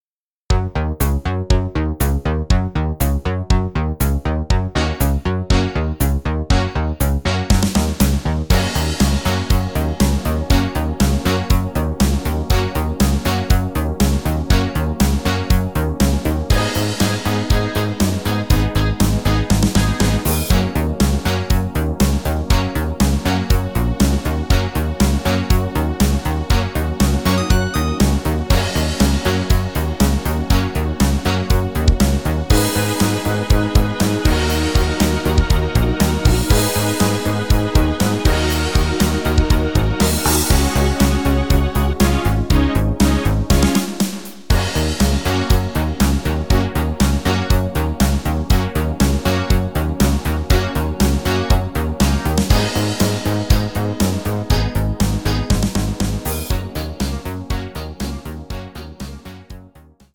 Playback abmischen  Playbacks selbst abmischen!
Super 80er für die gute Sängerin